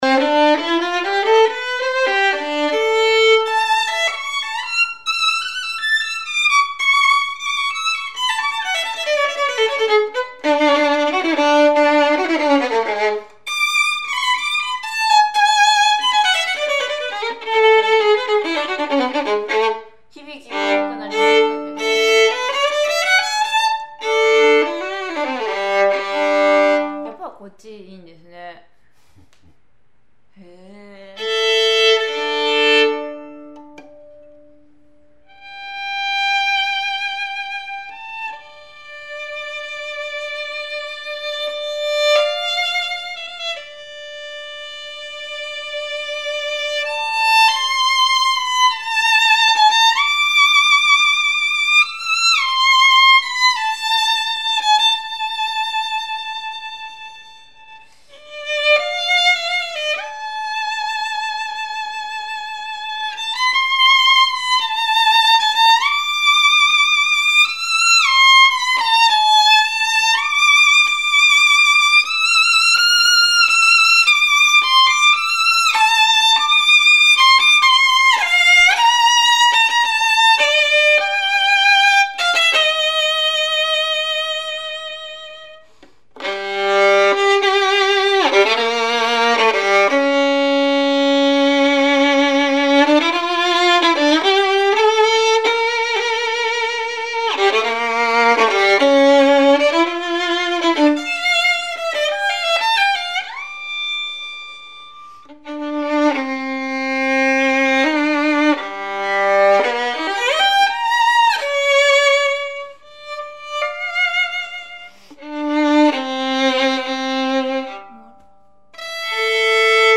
非常に深みの有る鳴りで、十分な音量があり、豊潤な音色を持っています。
各工房のコストパフォーマンスの高い音質を納得して頂く為にプロのソリストによる演奏をアップしました。
バイオリン